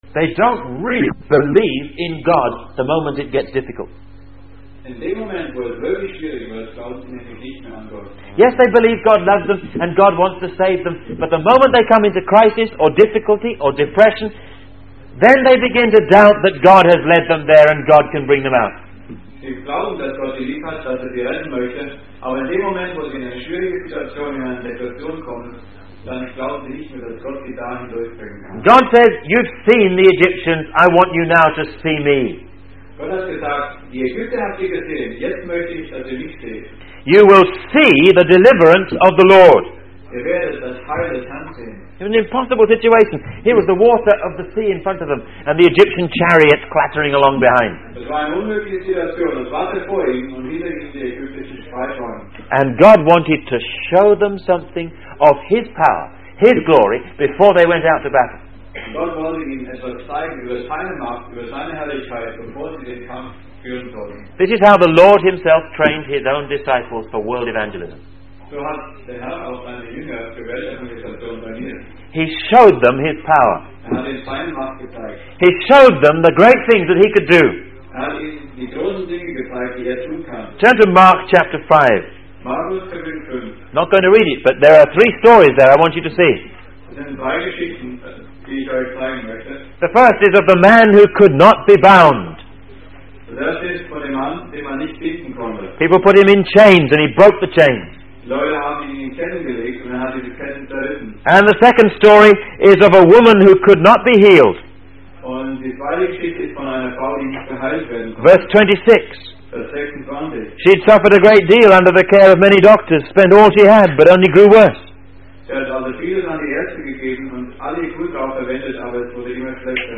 In this sermon, the speaker discusses the various lessons the Israelites learned from God during their journey in the wilderness.